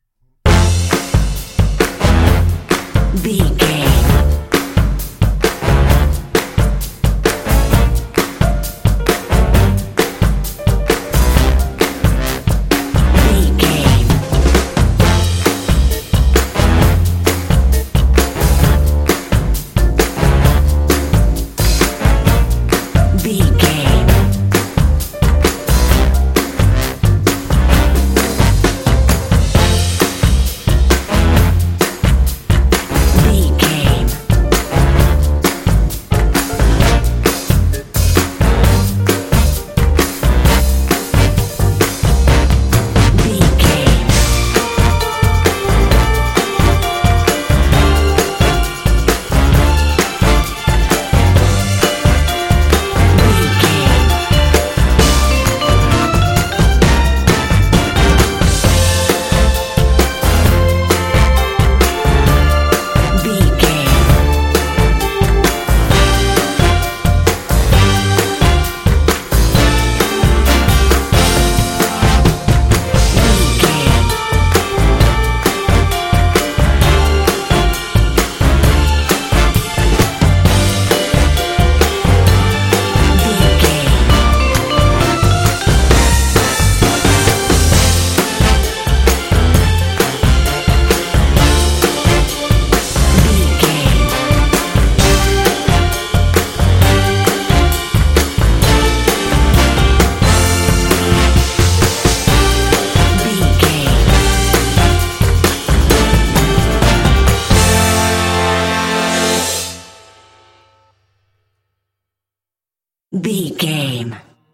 Uplifting
Ionian/Major
happy
bouncy
groovy
drums
brass
electric guitar
bass guitar
strings
rock and roll
big band